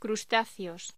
Locución: Crustáceos
voz